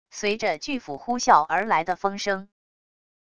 随着巨斧呼啸而来的风声wav音频